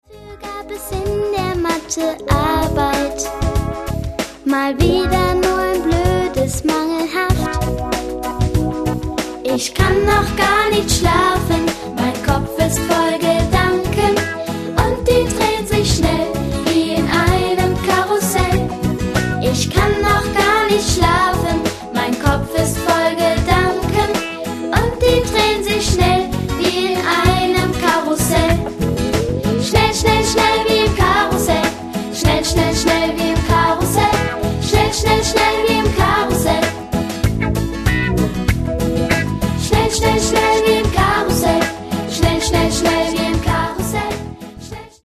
Die schönsten Gute-Nacht-Lieder
• Sachgebiet: Kinderlieder